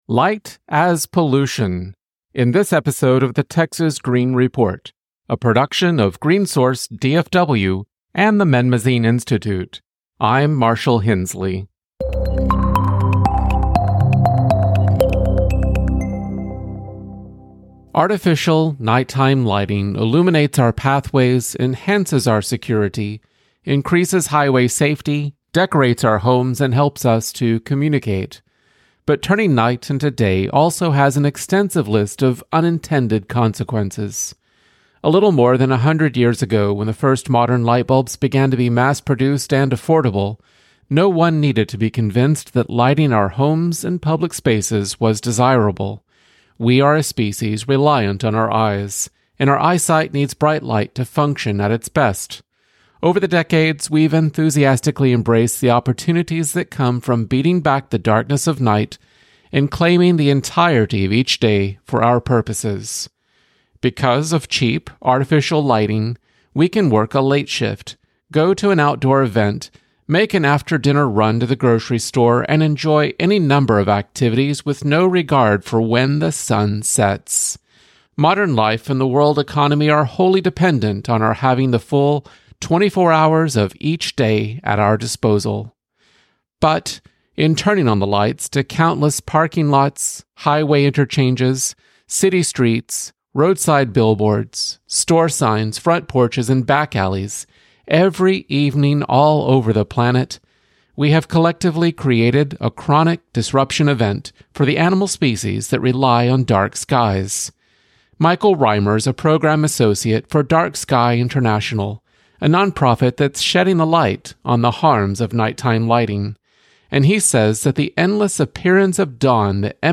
Listen to the podcast produced by Green Source DFW or read the transcript below.